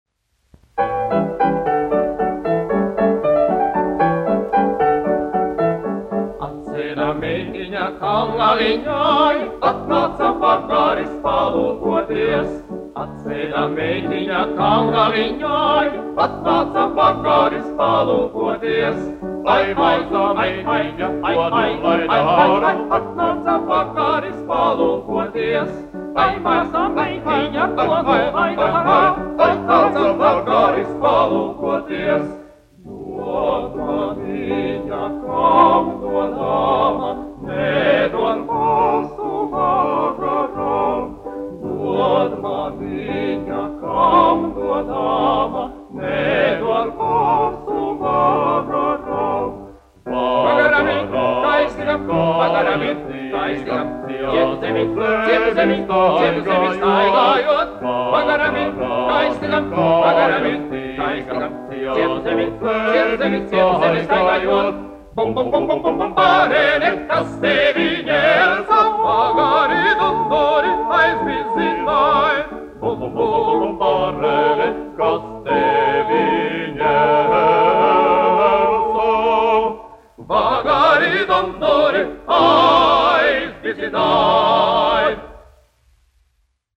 Maģais dancis : latviešu tautas deja
Latvijas Filharmonijas vīru vokālais kvartets, izpildītājs
1 skpl. : analogs, 78 apgr/min, mono ; 25 cm
Vokālie kvarteti
Latvijas vēsturiskie šellaka skaņuplašu ieraksti (Kolekcija)